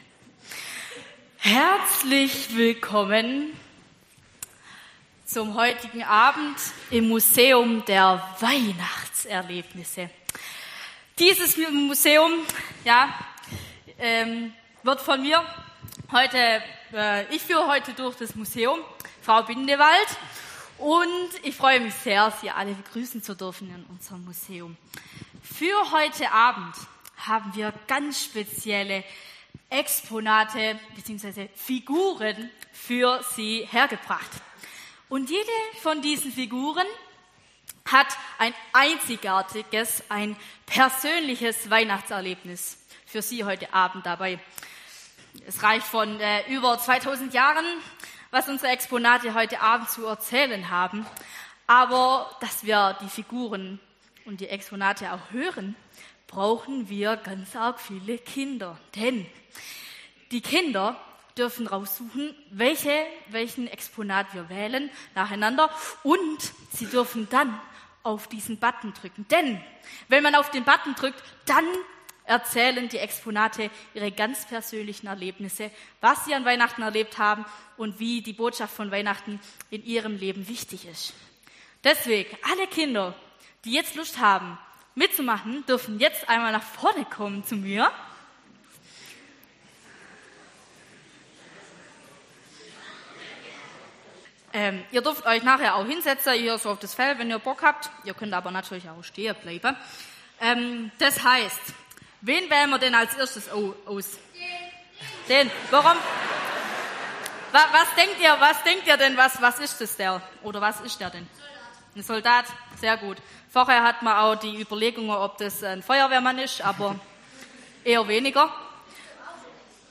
Predigtanspiel an Heilg Abend